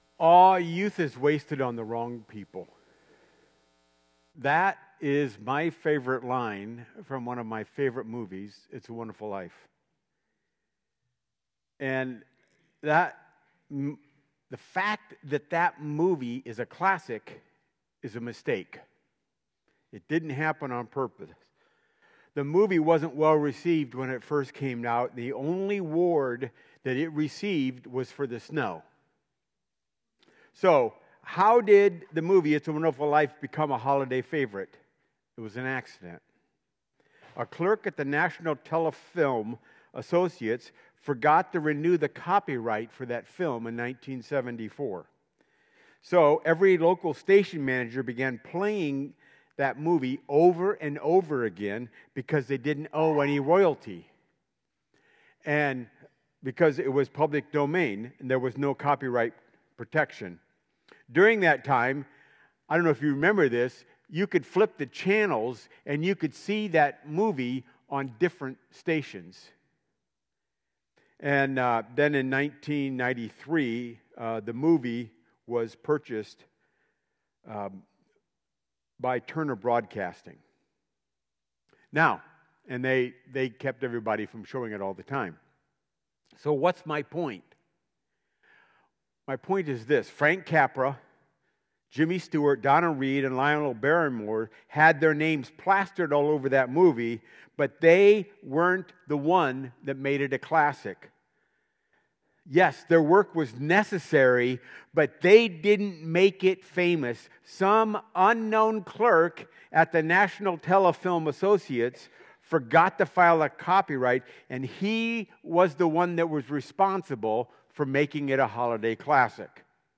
Sermons | Warsaw Missionary Church